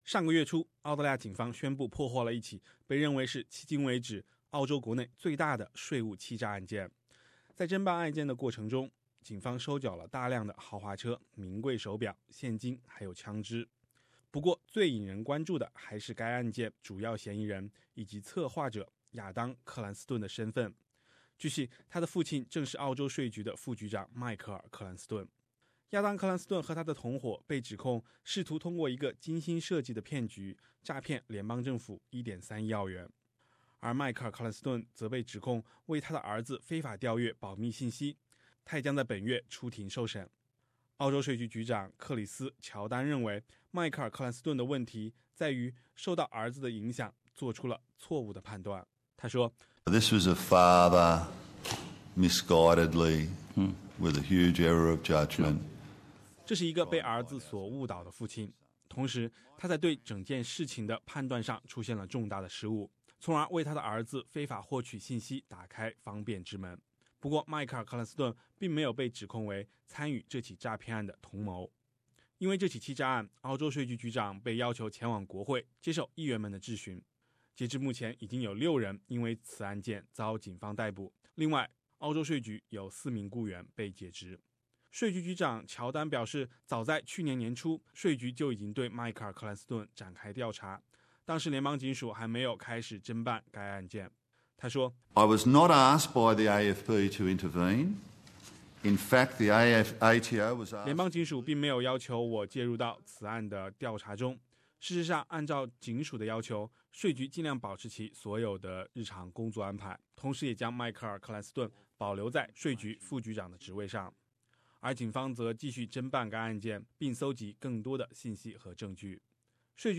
的报道